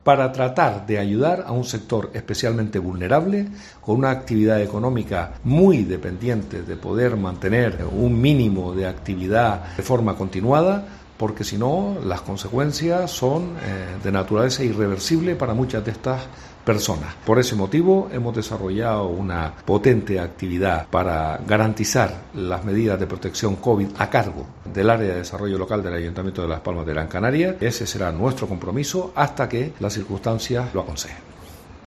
Pedro Quevedo, concejal de Desarrollo Local, Empleo y Turismo del Ayuntamiento de Las Palmas de Gran Canaria